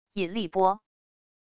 引力波wav音频